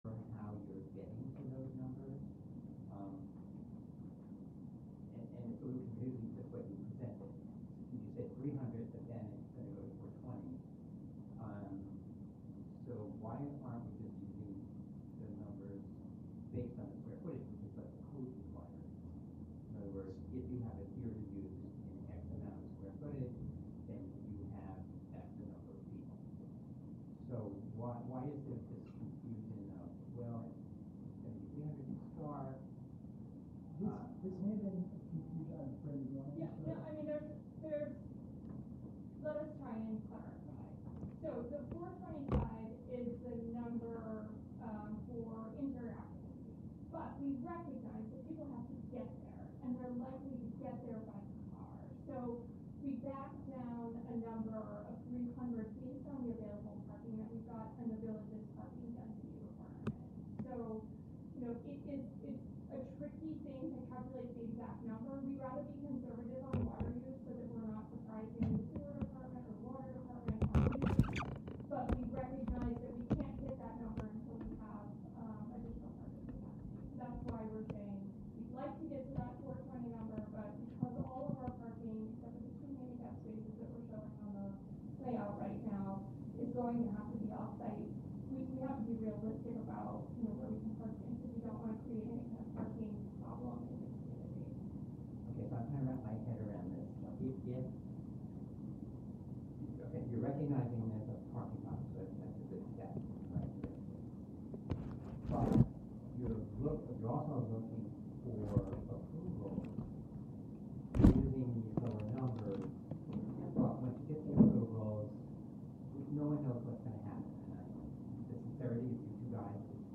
Live from the Village of Philmont: Planning Board Meeting (Audio)